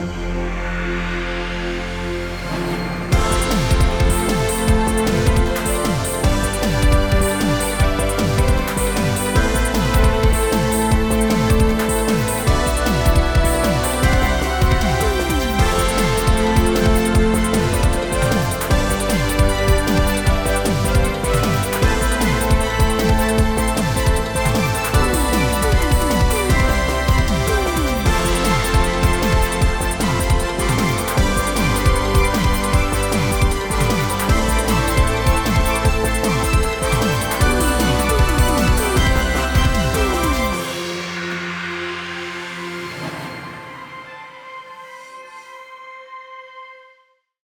80s JRPG – Soundtracks
This composition captures the essence of classic 80s JRPG soundtracks, featuring nostalgic synth melodies, rich harmonies, and expressive chiptune elements. Inspired by the golden era of role-playing games, the piece evokes a sense of adventure and emotion, bringing retro game worlds to life with an authentic vintage sound.